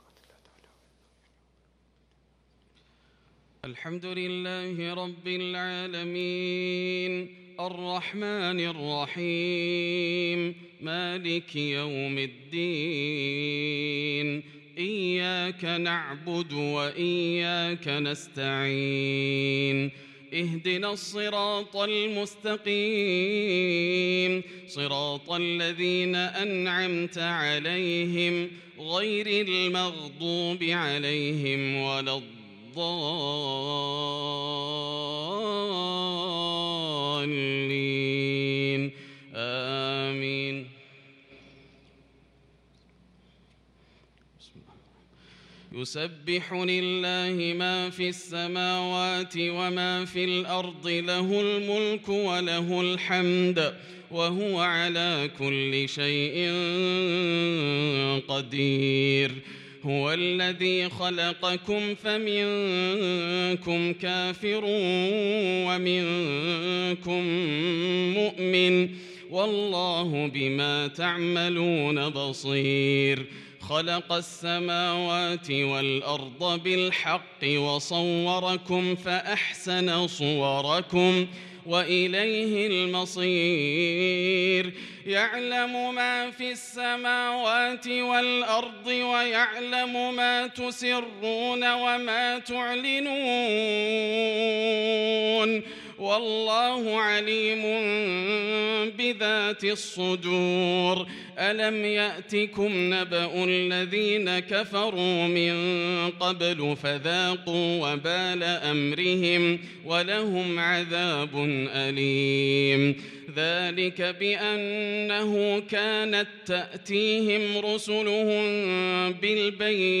صلاة العشاء للقارئ ياسر الدوسري 9 صفر 1443 هـ